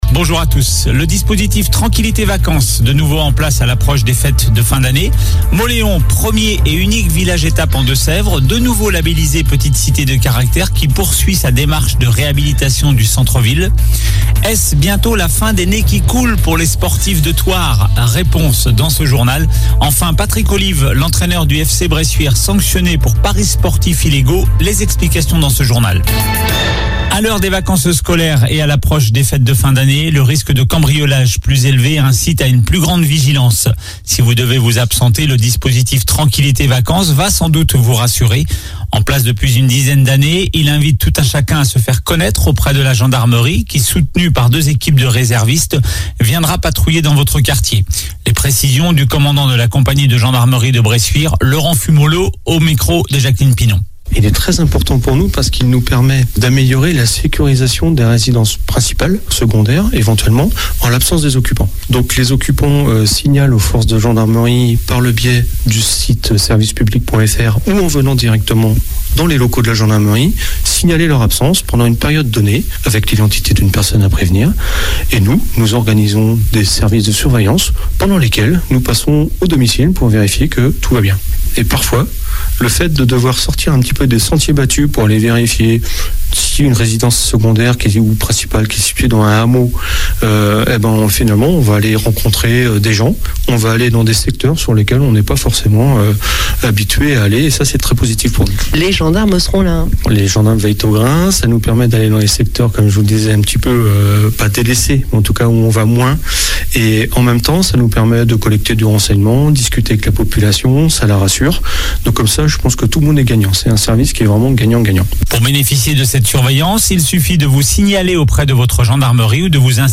Journal du mardi 20 décembre